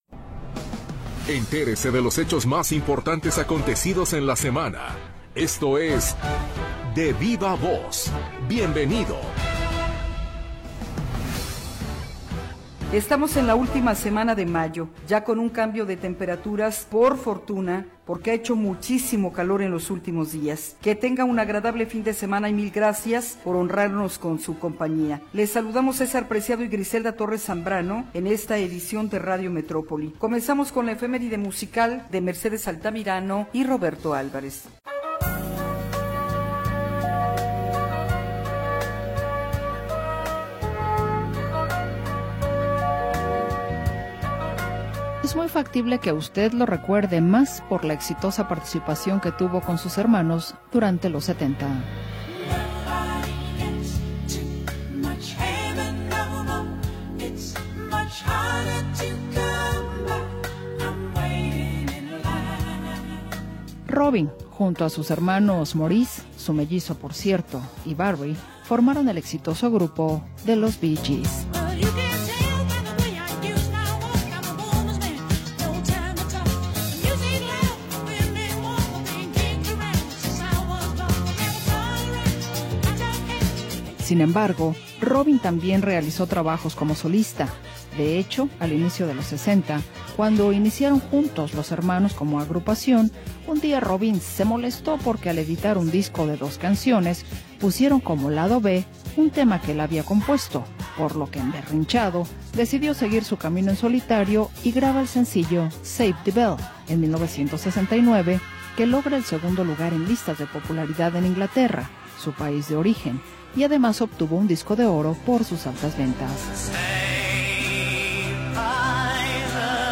Lo mejor de las entrevistas de la semana en Radio Metrópoli.